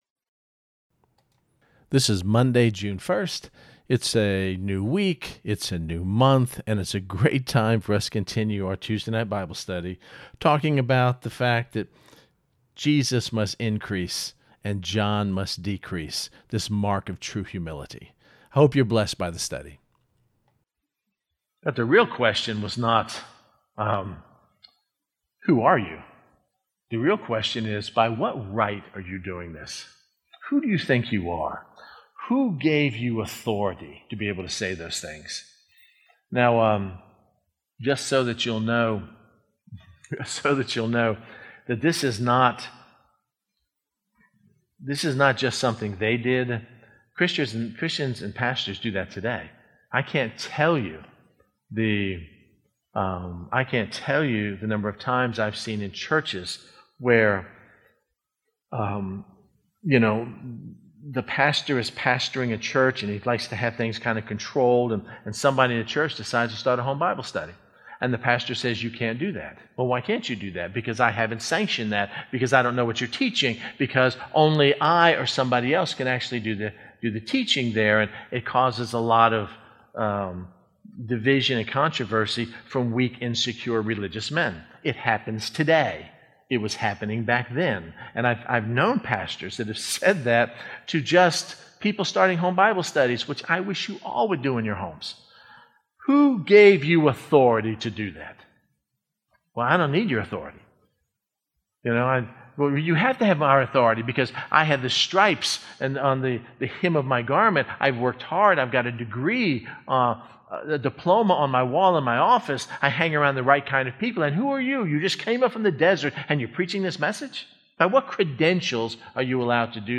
This is Part Four of last week’s Tuesday Night Bible Study, and we will be discovering even more about the character trait of John that led him to be used so mightily by our Lord.